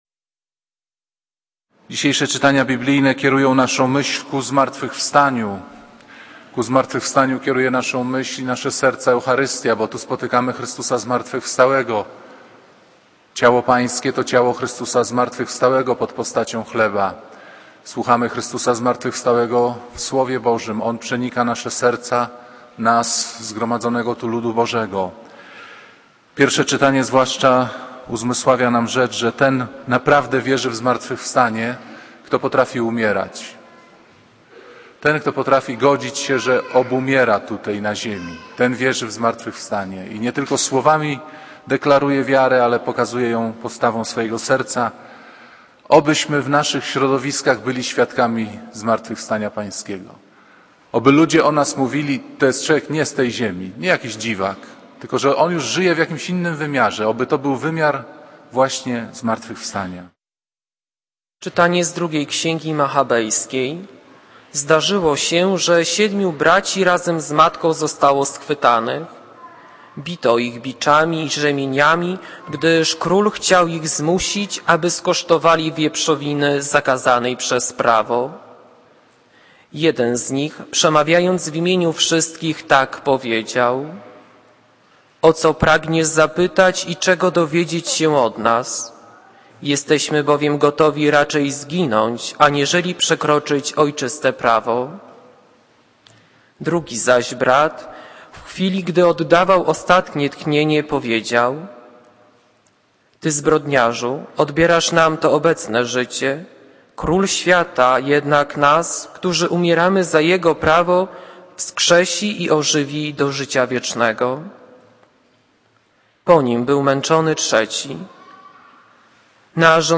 Kazanie z 7 listopada 2010 r.